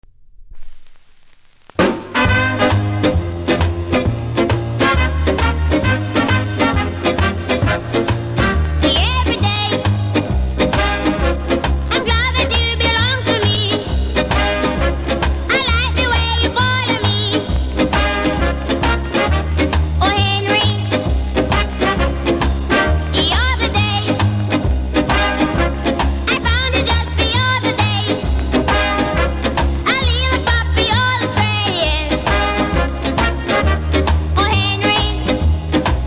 キズ多めで盤の見た目悪いですがノイズはキズほど感じないので試聴で確認下さい。